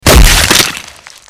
Impactful Damage Botão de Som
Sound Effects Soundboard317 views